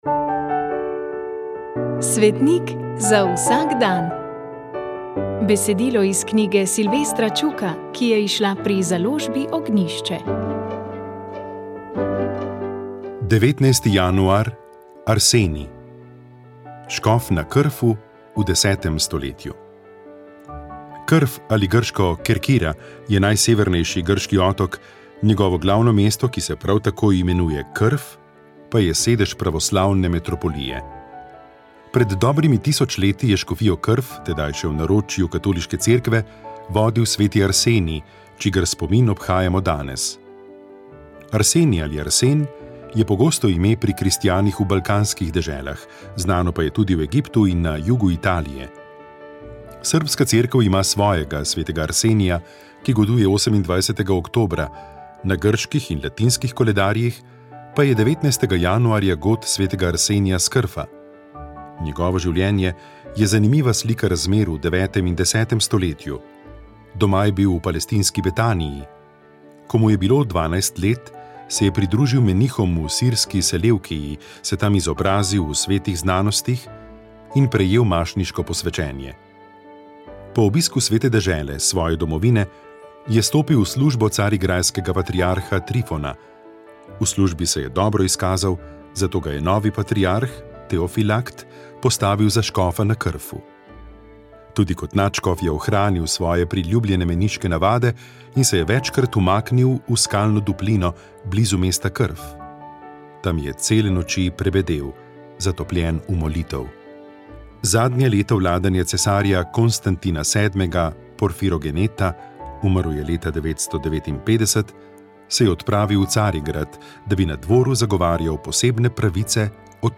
Komentar tedna